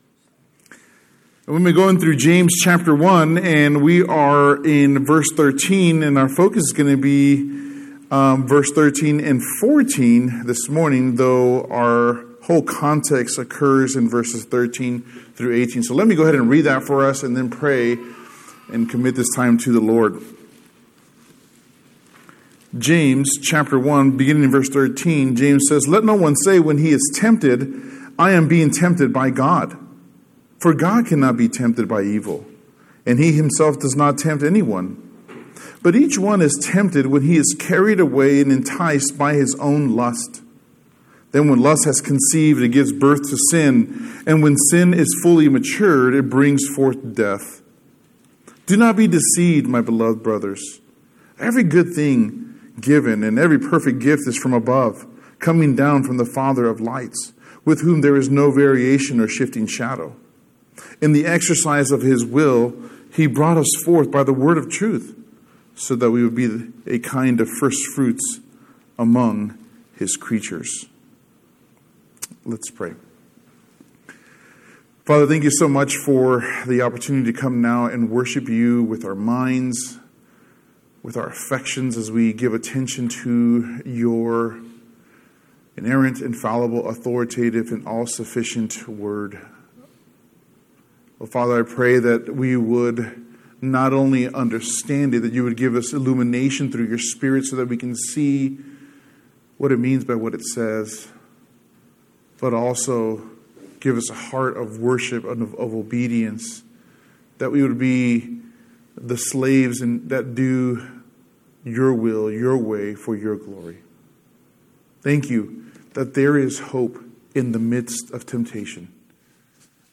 The message on Sunday